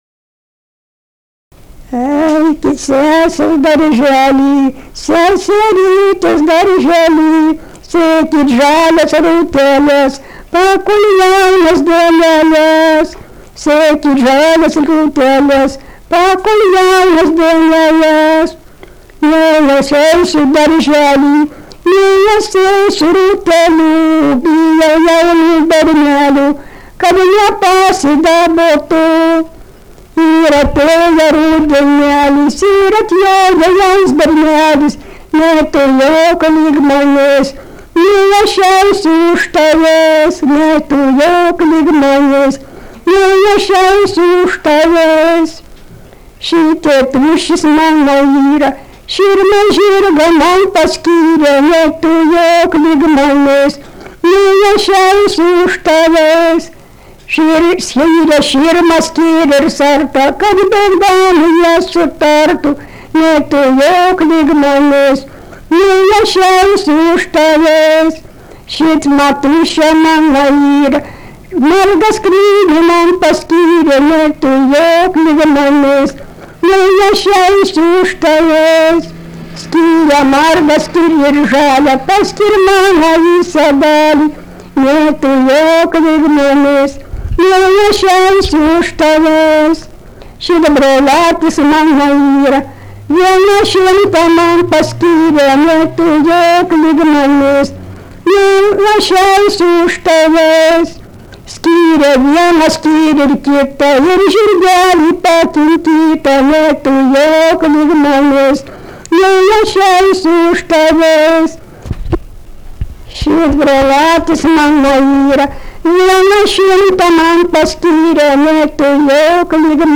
daina, vestuvių
Minčaičiai
vokalinis